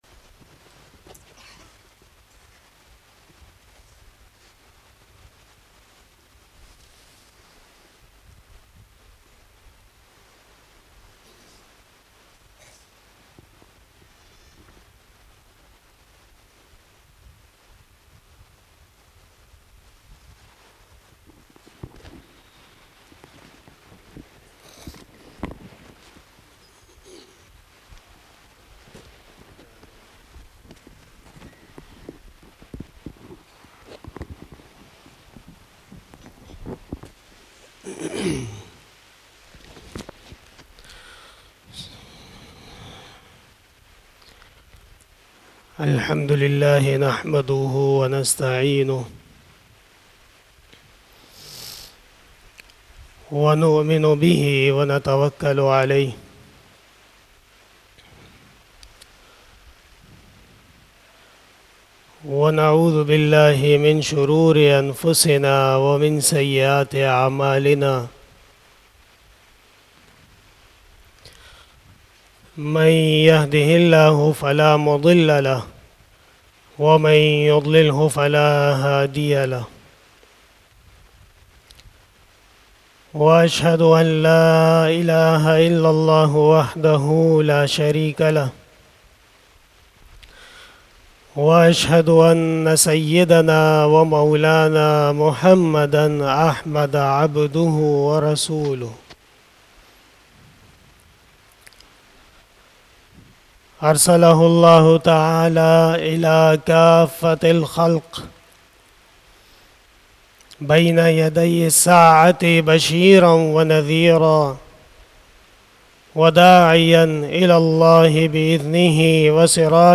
08 BAYAN E JUMA TUL MUBARAK 25 February 2022 (23 Rajab 1443H)
Khitab-e-Jummah 2022